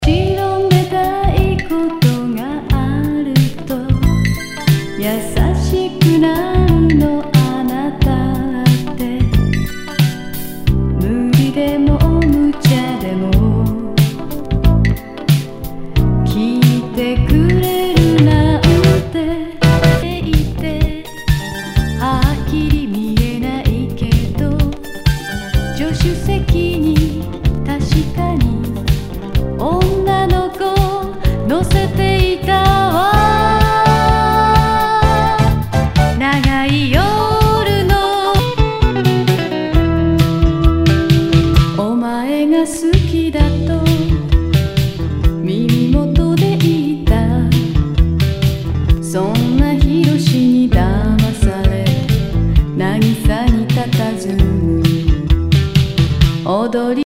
和モノ/JAPANEASE GROOVE
ナイス！シティポップ / Light Mellow 和モノ！！
全体にチリノイズが入ります